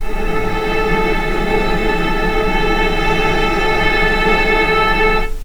vc_sp-A4-pp.AIF